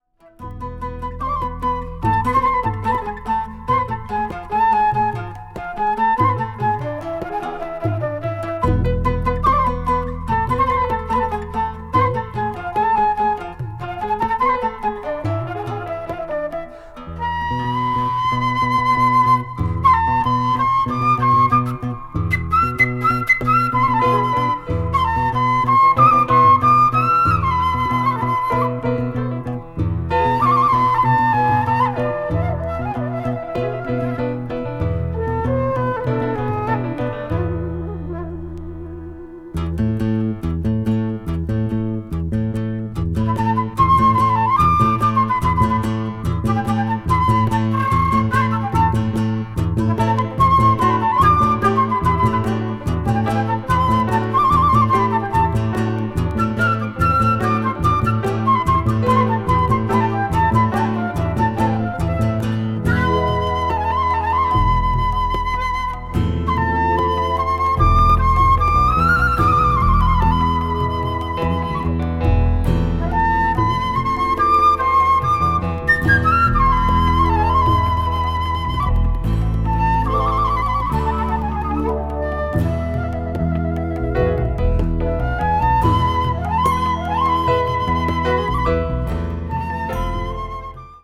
crossover   ethnic jazz   jazz funk   jazz groove